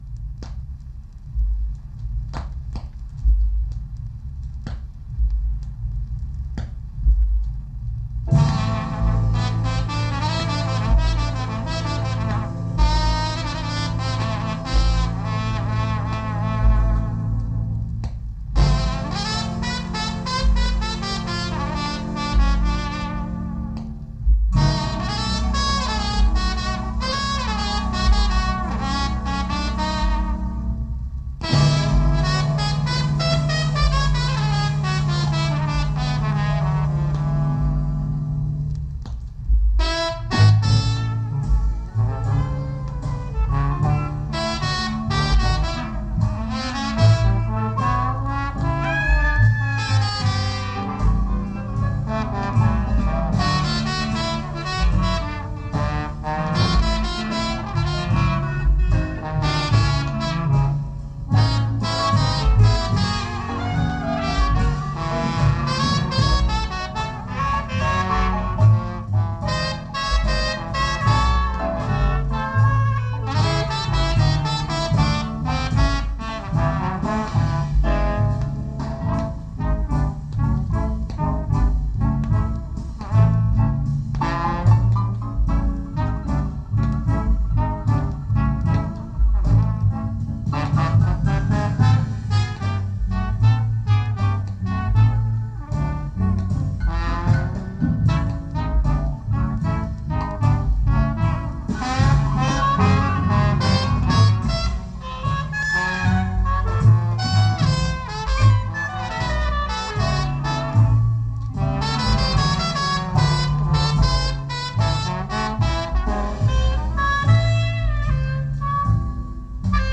Диксиленд
Запись с грампластинки. 1967.
труба
кларнет
тромбон
рояль
ударные
контрабас